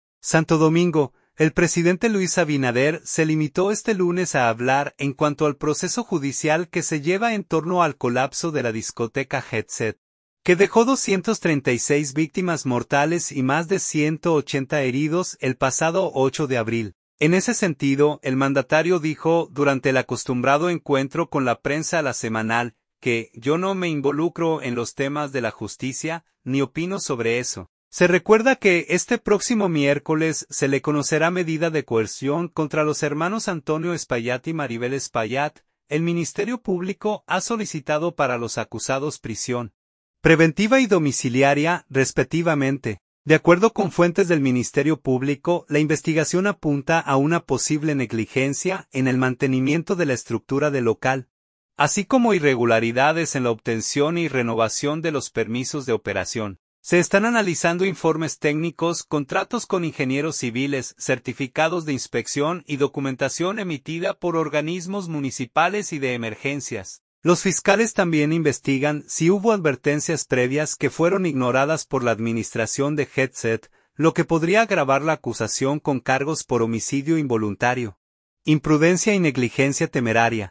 En ese sentido, el mandatario dijo durante el acostumbrado encuentro con la prensa LA Semanal, que: “Yo no me involucro en los temas de la justicia, ni opino sobre eso”.